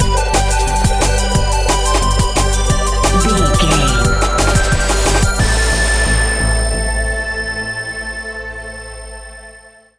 Aeolian/Minor
Fast
aggressive
dark
driving
energetic
groovy
drum machine
synthesiser
electronic
sub bass
synth leads
synth bass